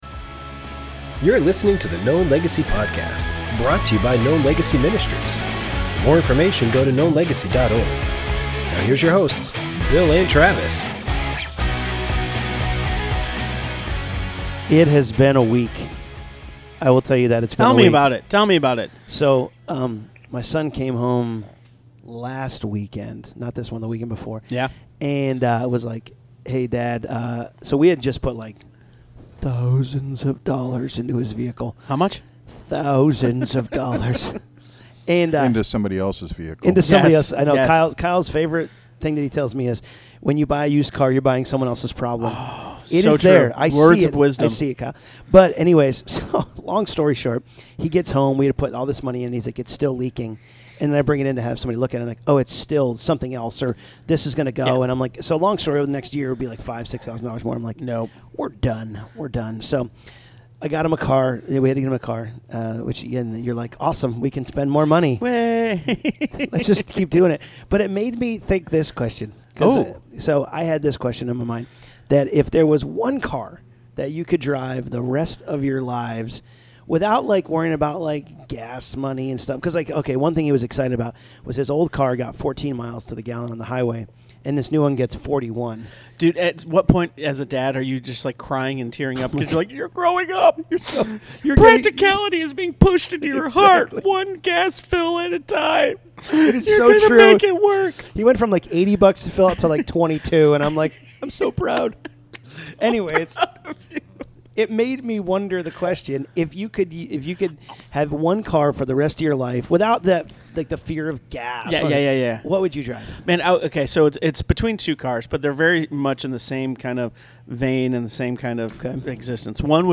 Think you dont have a testimony? Think again! Interview